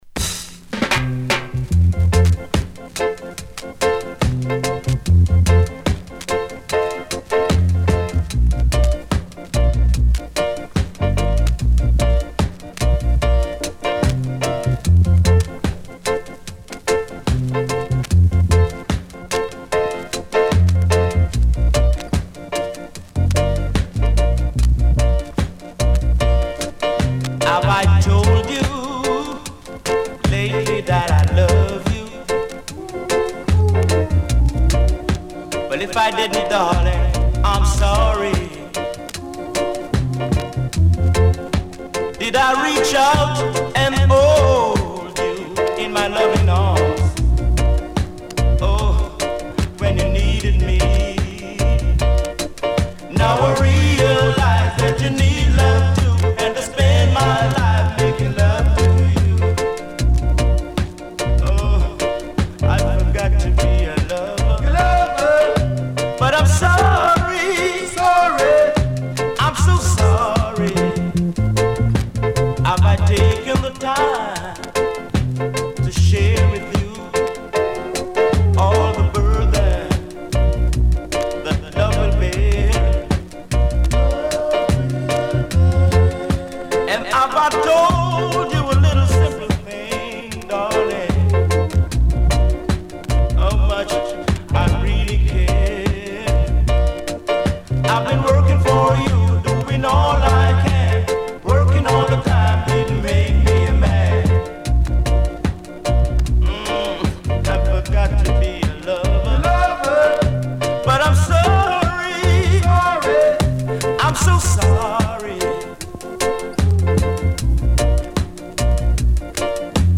Slowing the tempo down with the original
Keyboards
with his trademark attacking, military style
drums
Bass